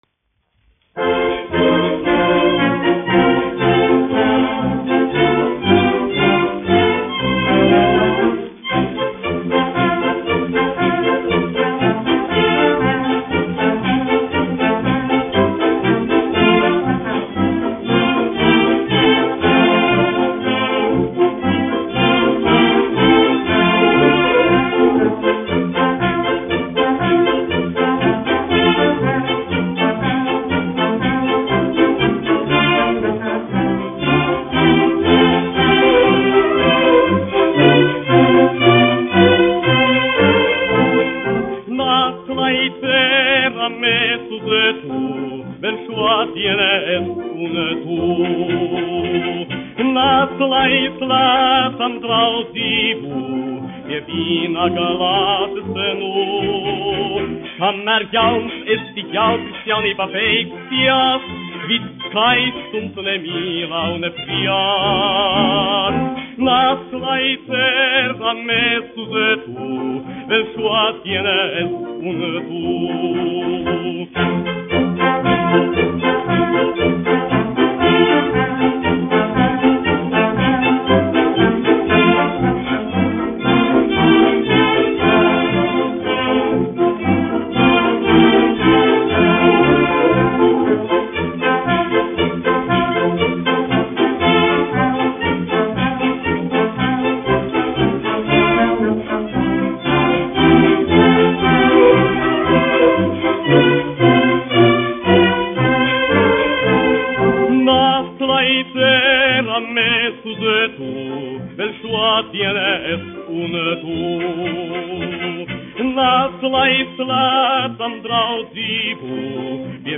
dziedātājs
1 skpl. : analogs, 78 apgr/min, mono ; 25 cm
Populārā mūzika
Fokstroti
Latvijas vēsturiskie šellaka skaņuplašu ieraksti (Kolekcija)